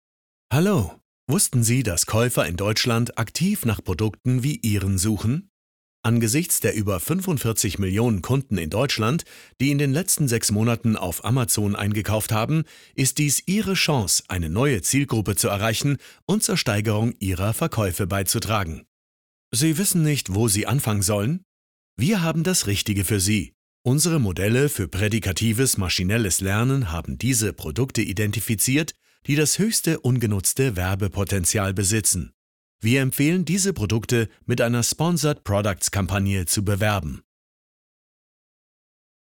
Male
Assured, Character, Confident, Cool, Corporate, Deep, Engaging, Friendly, Gravitas, Natural, Reassuring, Sarcastic, Soft, Wacky, Warm, Witty, Versatile, Young
German (native), Bavarian (native), English with German accent (native), German with English accent, English with South American Accent, German with several European foreign accents.
Microphone: Neumann U87, Neumann M149, Brauner Phantom Anniversary Edition etc.